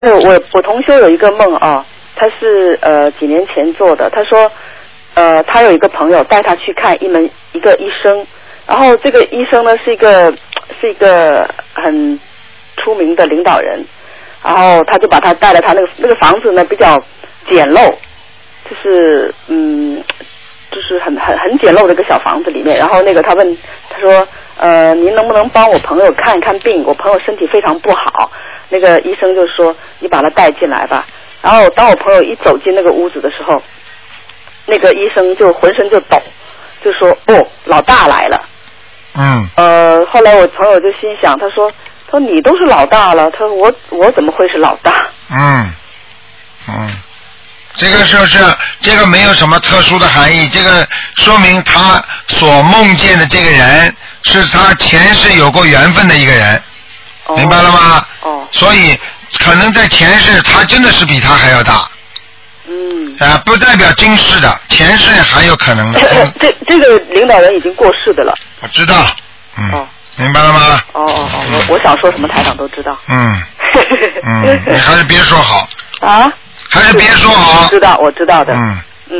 目录：2012年02月_剪辑电台节目录音集锦